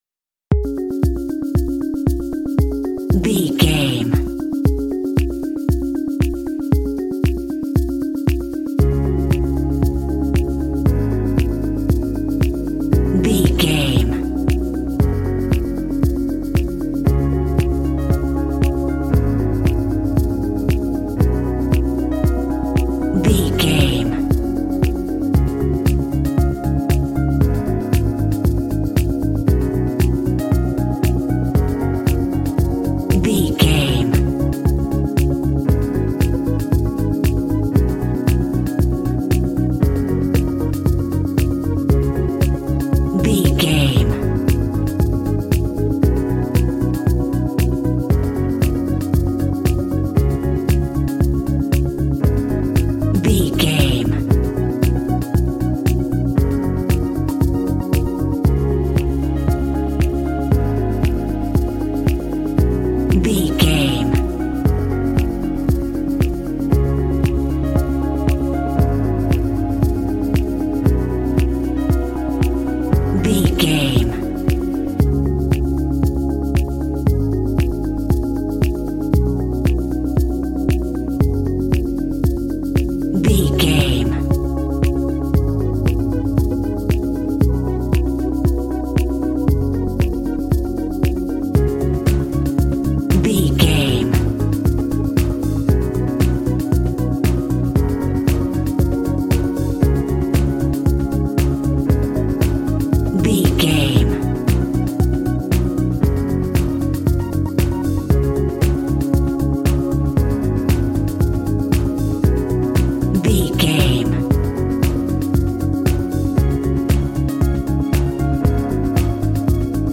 Mixolydian
C#
cool
chill
motivational
bright
strings
piano
bass guitar
drums
percussion
synthesiser
alternative rock
pop
industrial